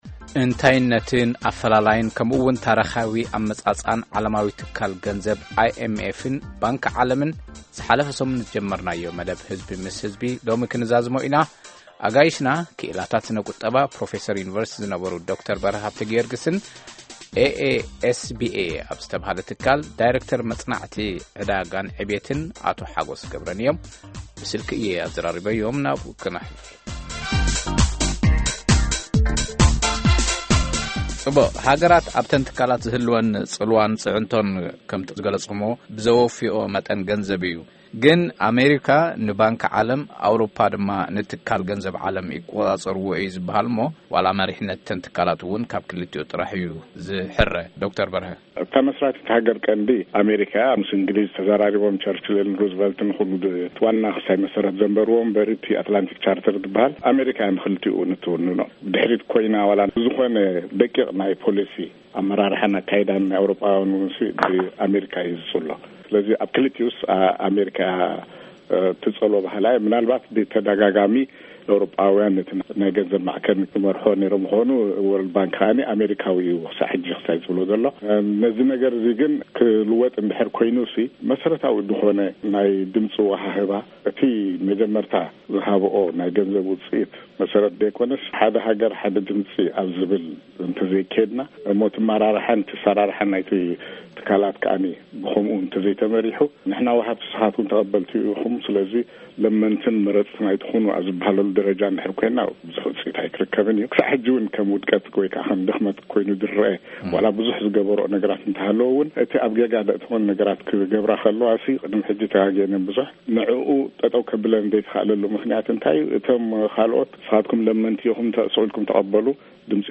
መዘራረቢ መደብ ህዝቢ ምስ ህዝቢ